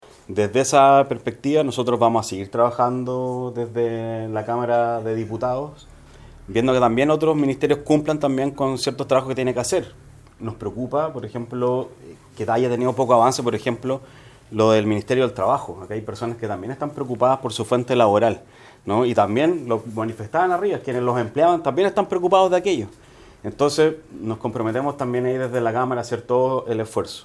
En otro plano, el diputado Alejandro Bernales expuso que deberá exigir al ministerio del Trabajo que realice una labor de apoyar a quienes eran dependientes de establecimientos comerciales y por el incendio perdieron su fuente laboral.